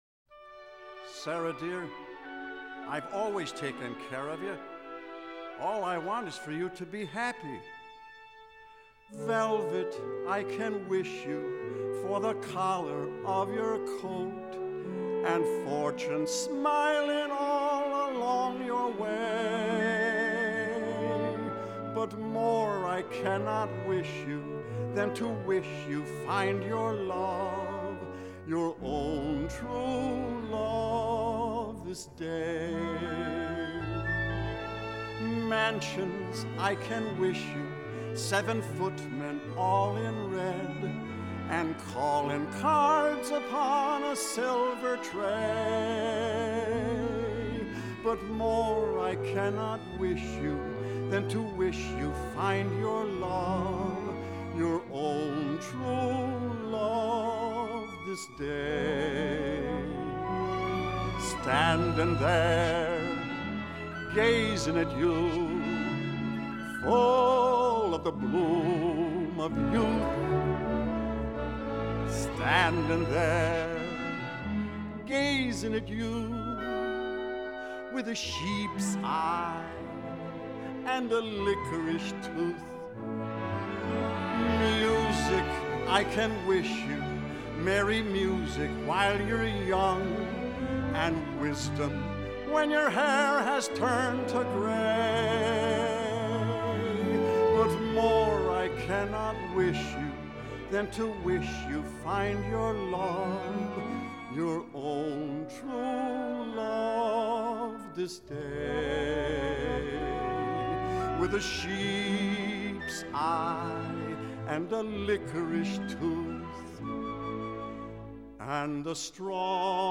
1950   Genre: Musical   Artist